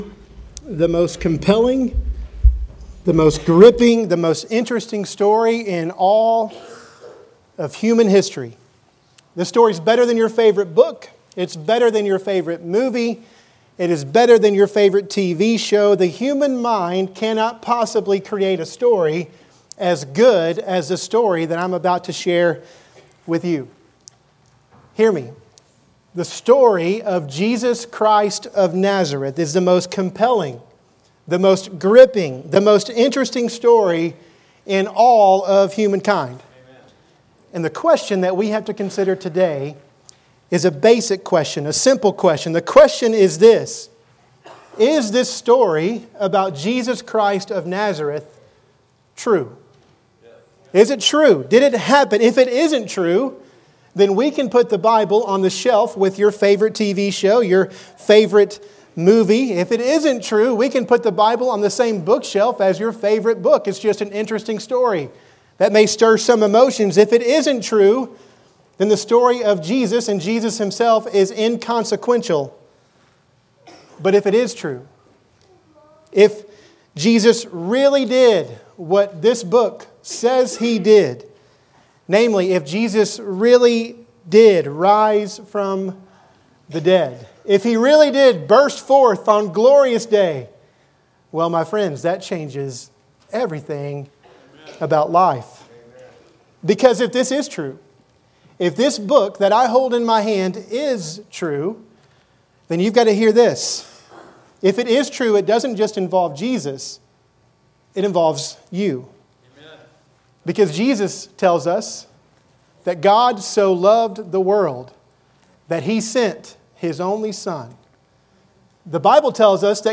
Sermons | First Baptist Church Moberly